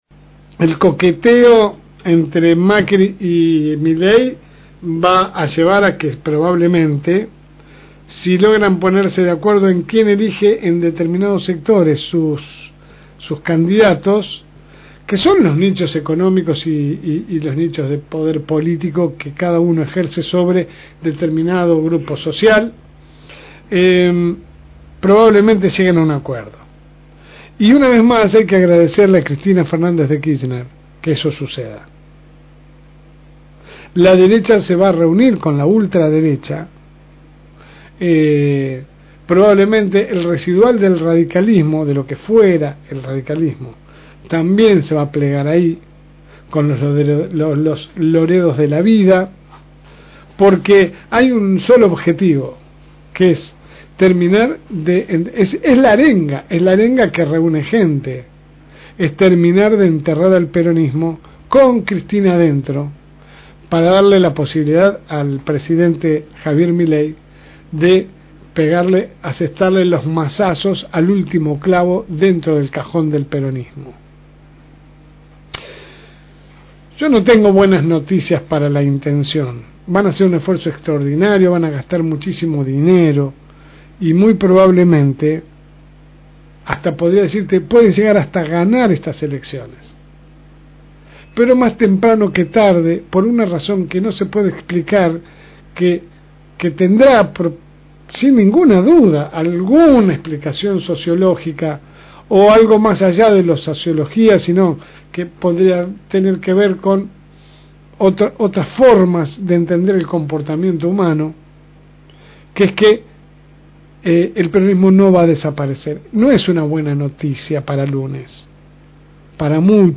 AUDIO – Editorial de la LSM.